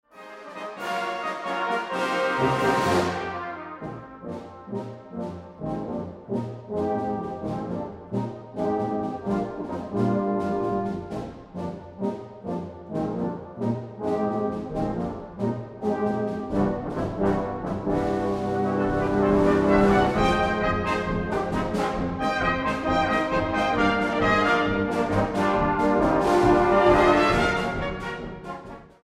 STYLE: Brass Band